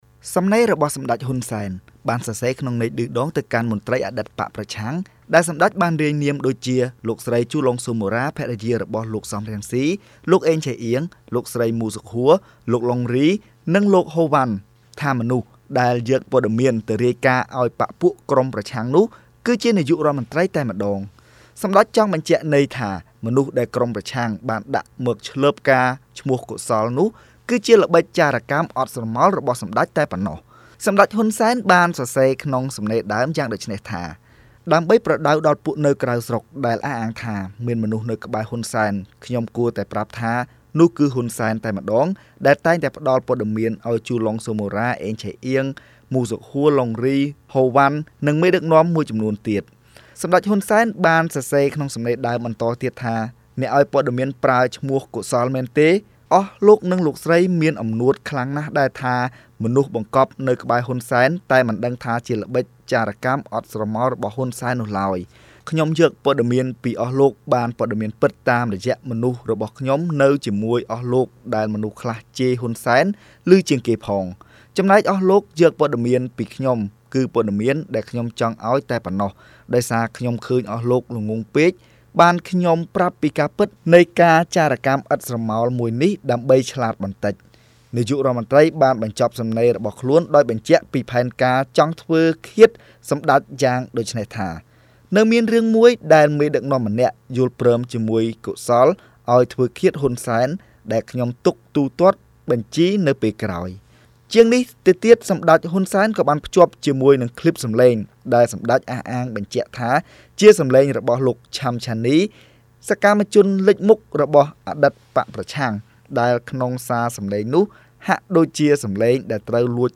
ជូនសេចក្តីរាយការណ៍